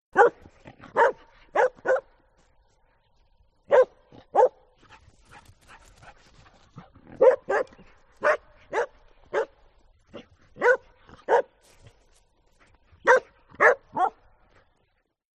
دانلود صدای واق واق سگ و ورجه ورجه زیاد از ساعد نیوز با لینک مستقیم و کیفیت بالا
جلوه های صوتی